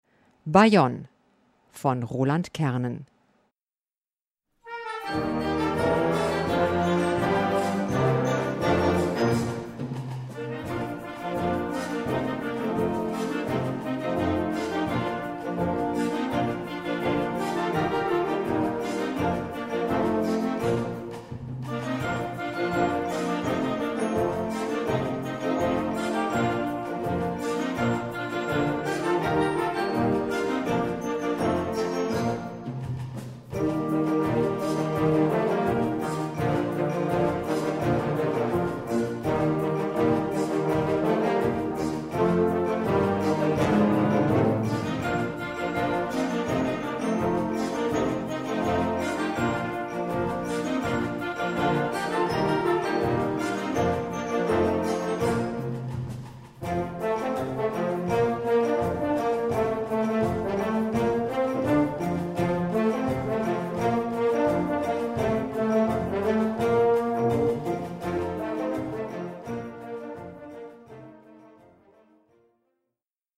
Gattung: Jugendwerk (Flex Serie)
Besetzung: Blasorchester